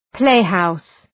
Προφορά
{‘pleıhaʋs}